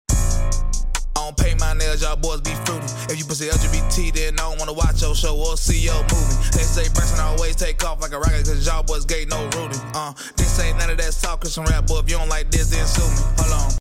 Christian Rap